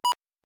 Subscribe Radio Beep - Custom Radio Beep.
radio_beep.ogg